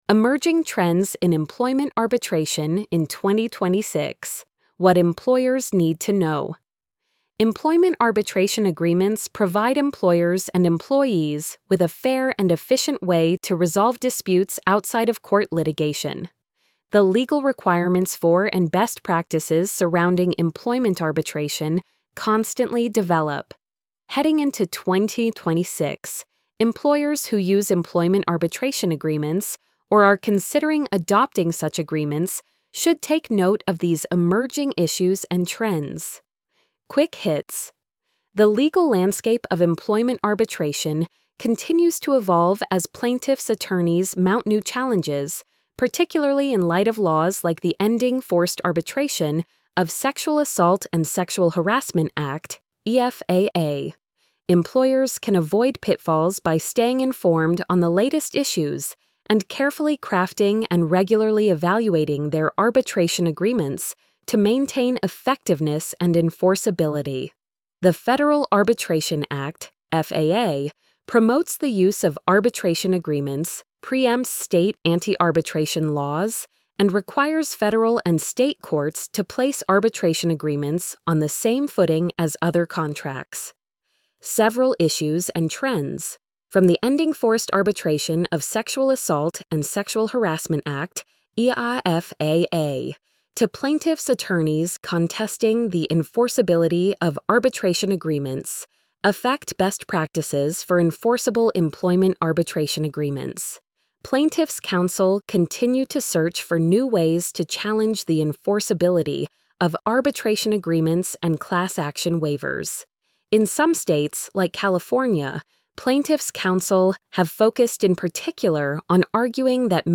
emerging-trends-in-employment-arbitration-in-2026-what-employers-need-to-know-tts.mp3